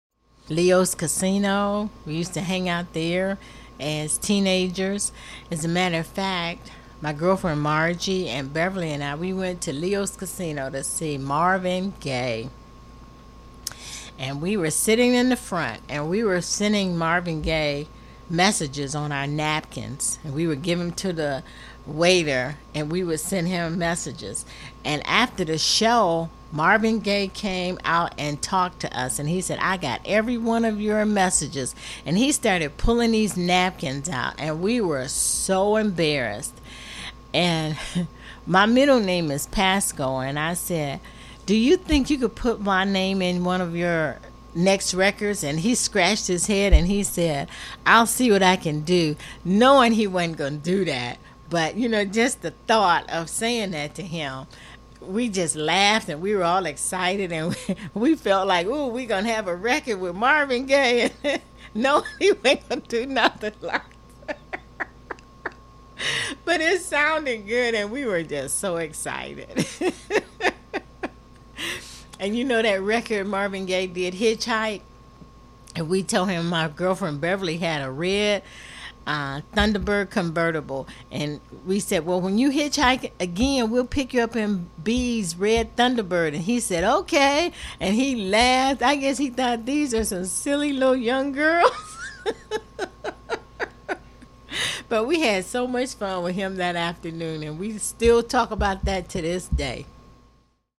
Source Cleveland Regional Oral History Collection